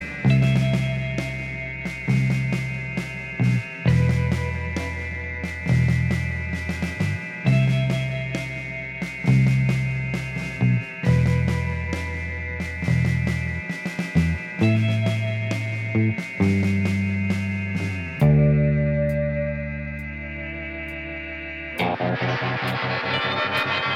Minus Lead Guitar Pop (1980s) 4:54 Buy £1.50